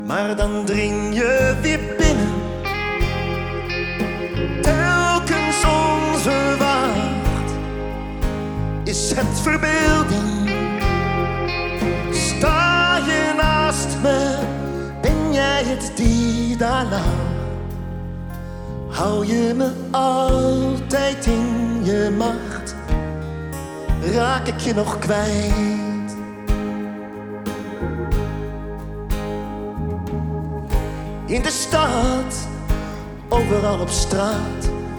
Жанр: Поп
# Pop